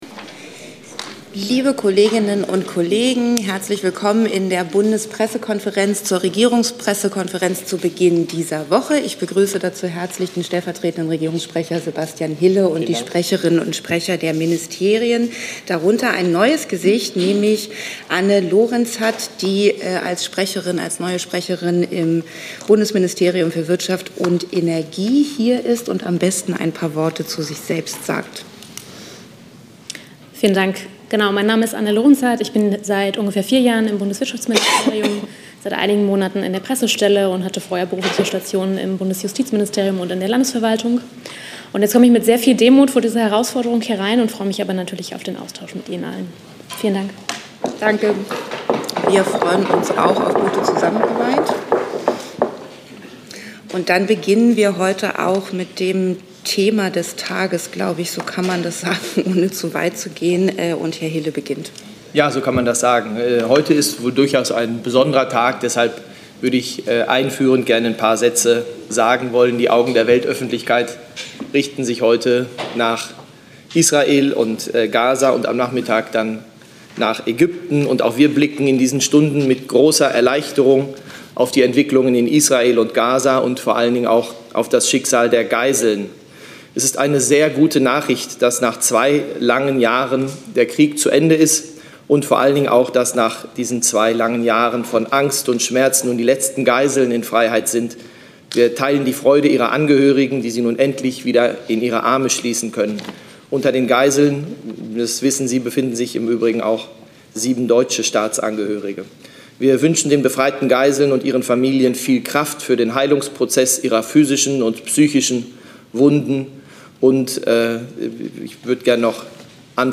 Regierungspressekonferenz in der BPK vom 13. Oktober 2025